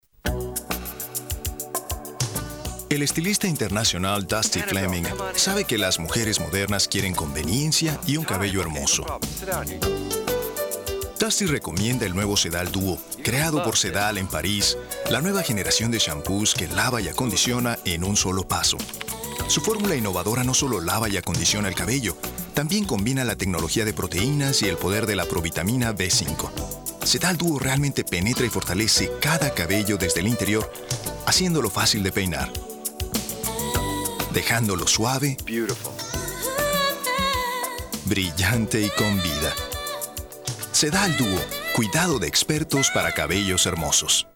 Spanish, Mexican. Producer, journalist, flexible, resonant, seasoned pro.
Latin American Spanish Commercial